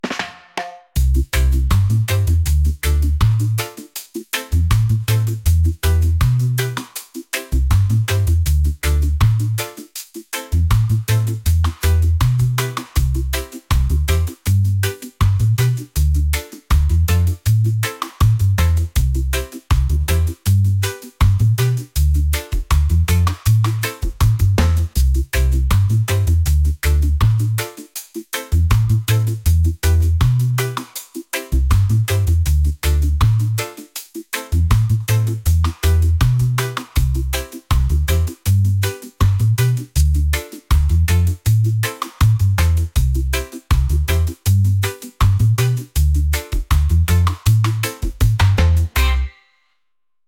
reggae | pop | folk